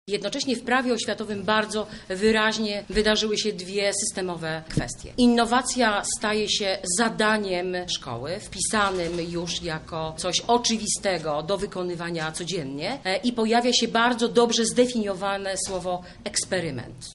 – mówi Anna Zalewska, minister edukacji